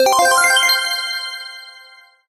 get_star_points_01.ogg